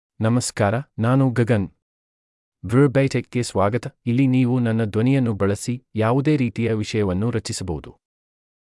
Gagan — Male Kannada AI voice
Gagan is a male AI voice for Kannada (India).
Voice sample
Listen to Gagan's male Kannada voice.
Gagan delivers clear pronunciation with authentic India Kannada intonation, making your content sound professionally produced.